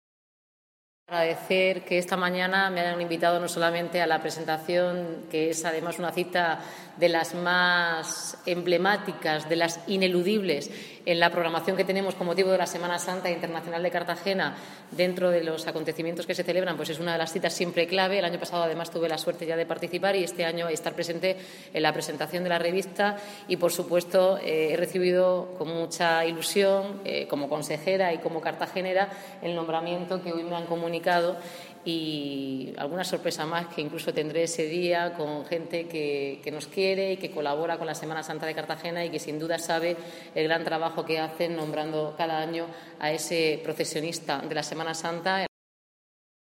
Contenidos Asociados: Declaraciones de la consejera de Cultura y portavoz del Gobierno regional, Noelia Arroyo - "Procesionista de Honor" por la Asociación Procesionista del Año de Cartagena (Documento [.mp3] 0,71 MB) Formato: jpeg Tamaño: 543,26 KB.